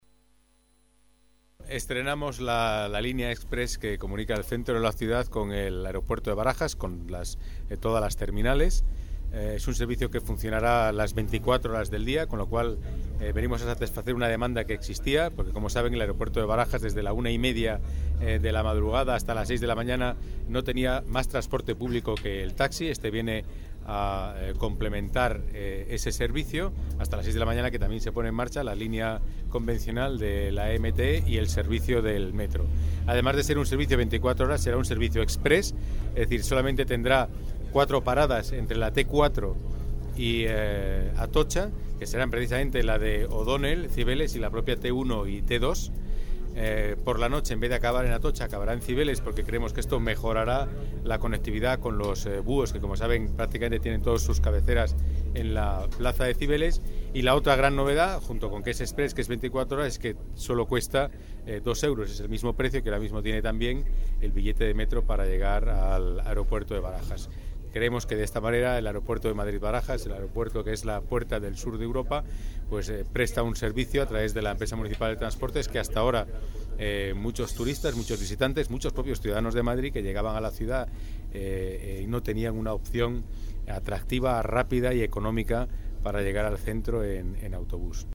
Nueva ventana:Declaraciones de Pedro Calvo, delegado de Movilidad